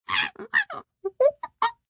AV_monkey_long.ogg